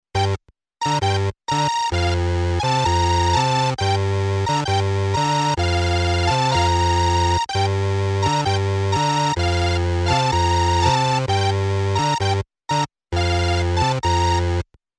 8 bit Gaming Musik
Tempo: langsam / Datum: 15.08.2019